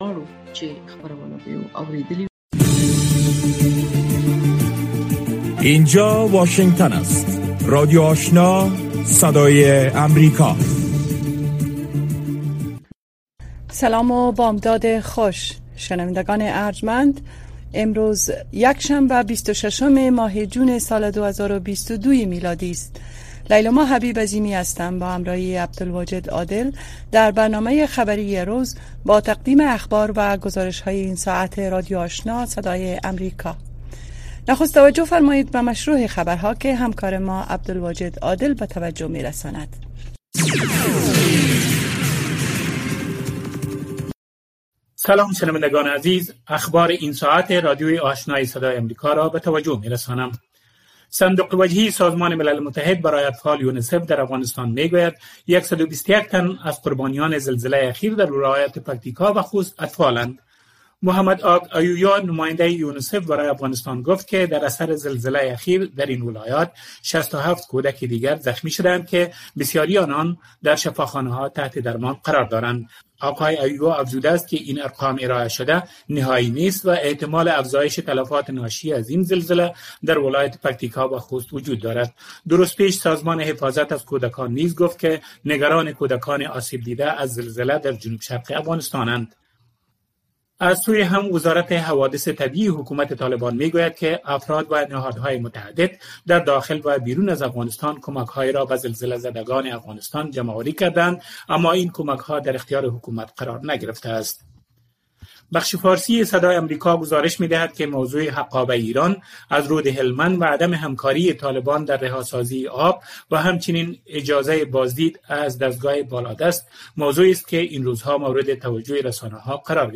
برنامۀ خبری صبحگاهی